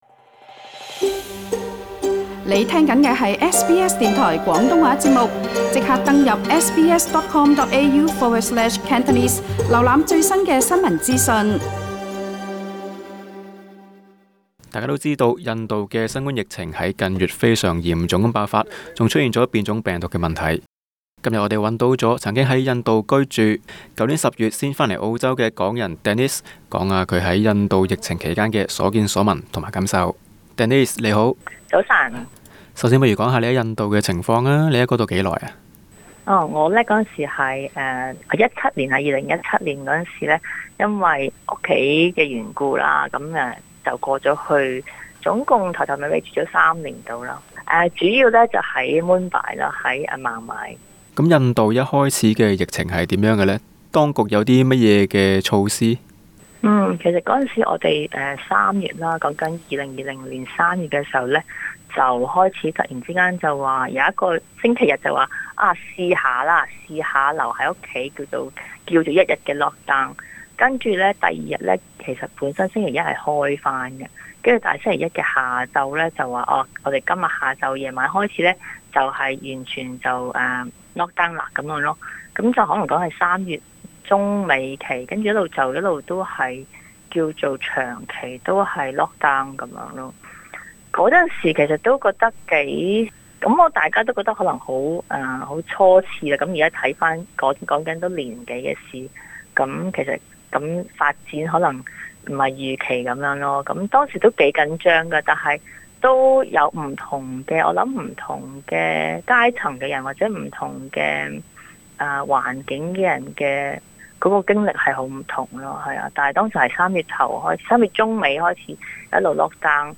社區訪問